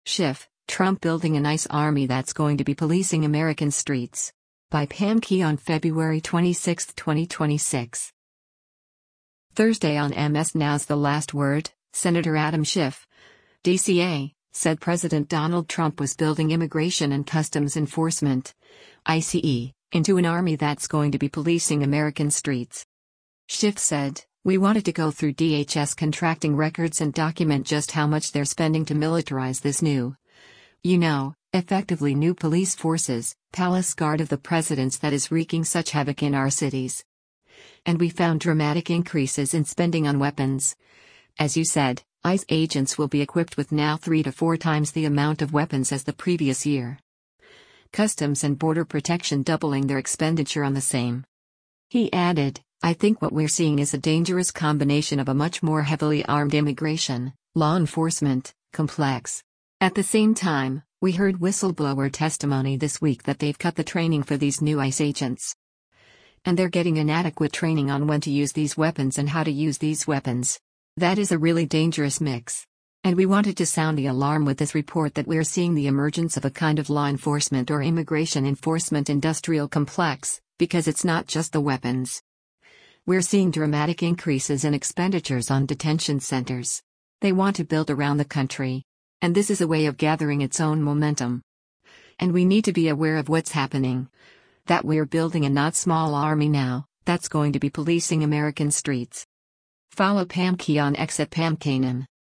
Thursday on MS NOW’s “The Last Word,” Sen. Adam Schiff (D-CA) said President Donald Trump was building Immigration and Customs Enforcement (ICE) into an army “that’s going to be policing American streets.”